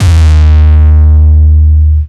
Long Dist 808 (D#)1.wav